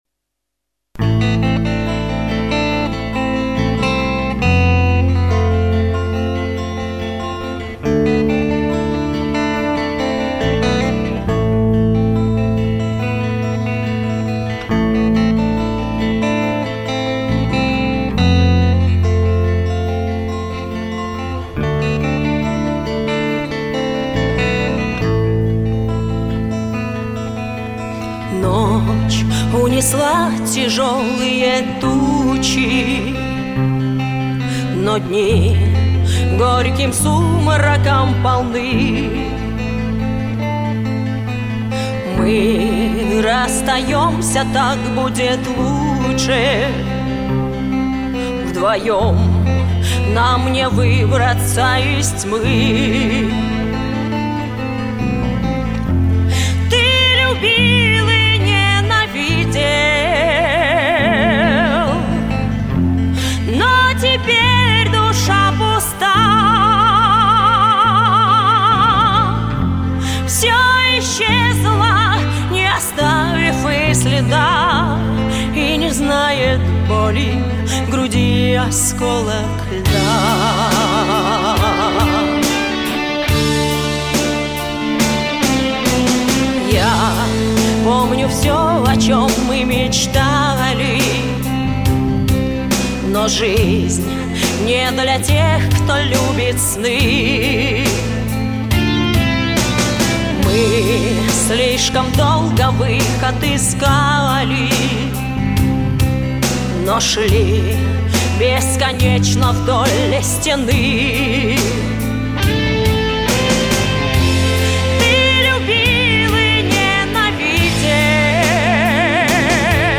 Меццо-сопрано